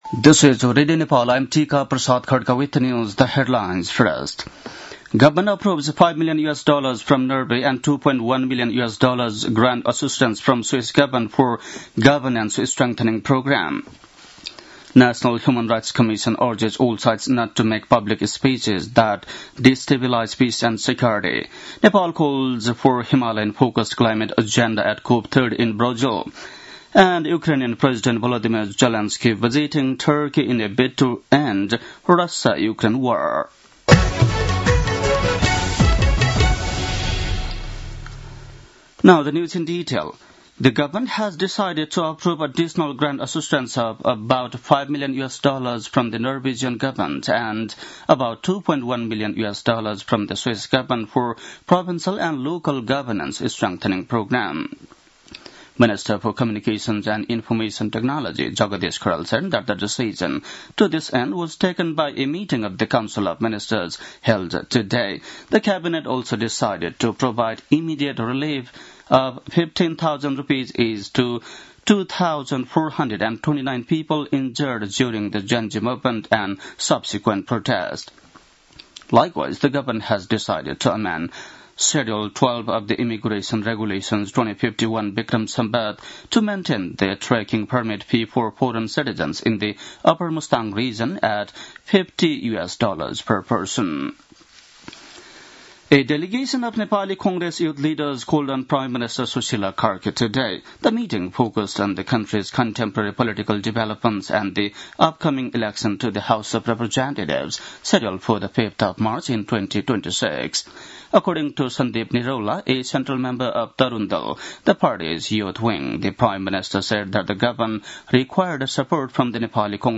बेलुकी ८ बजेको अङ्ग्रेजी समाचार : २ मंसिर , २०८२